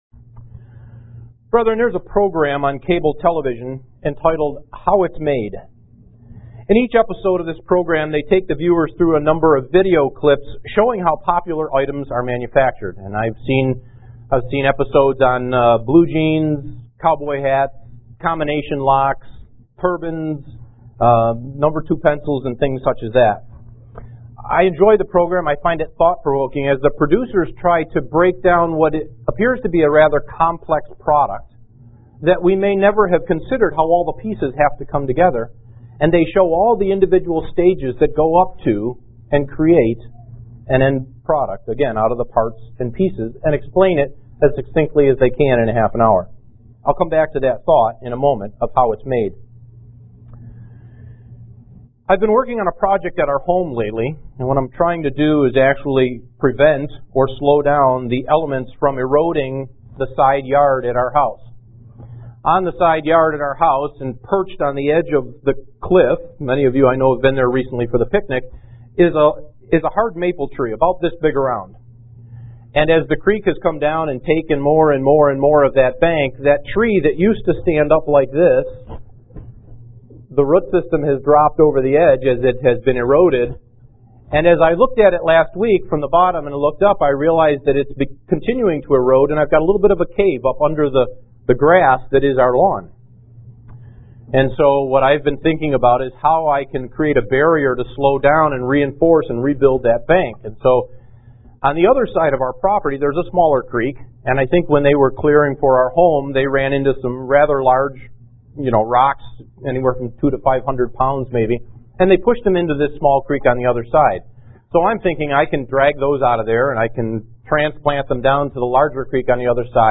Given in Buffalo, NY Elmira, NY
UCG Sermon Studying the bible?